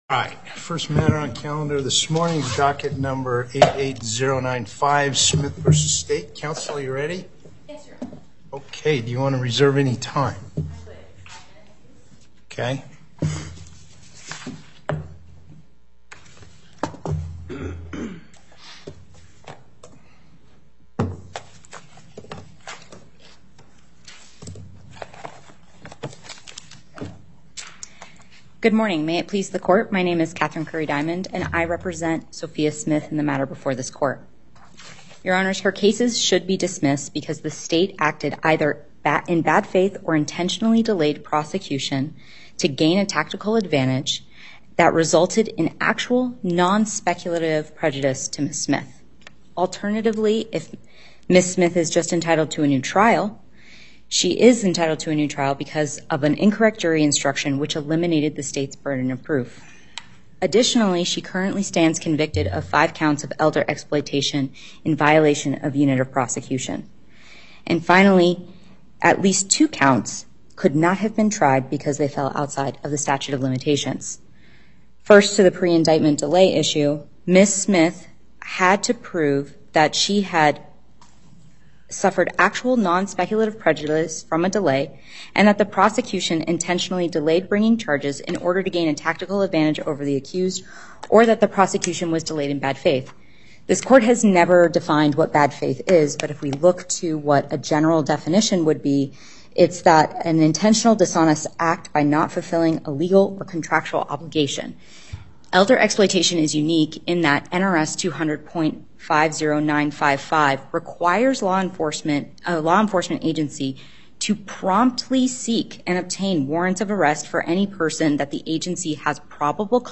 Before Panel A25, Justice Parraguirre presiding Appearances